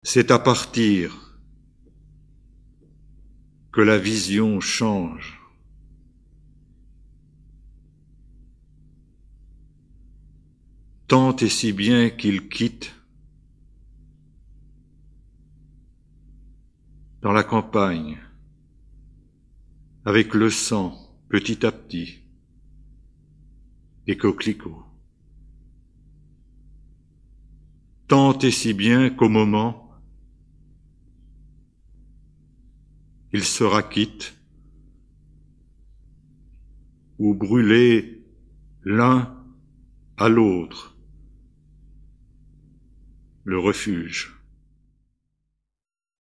illustration sonore